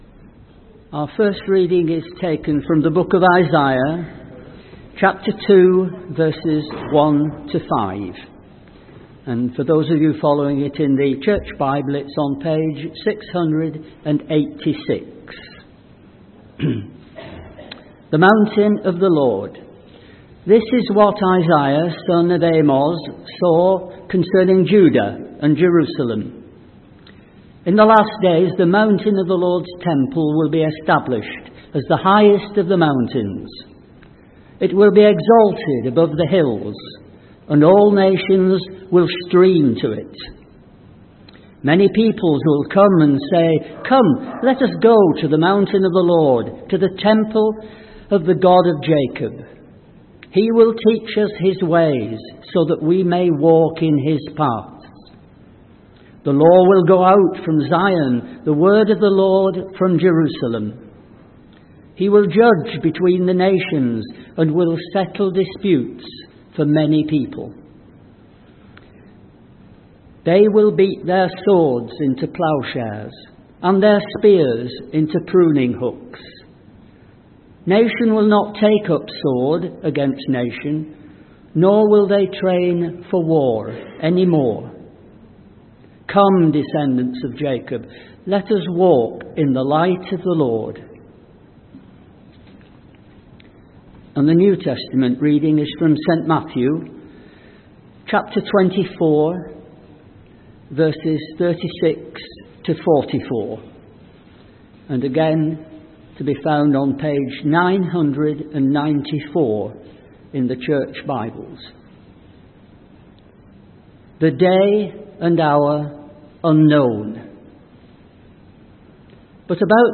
From Service: "10.45am Service"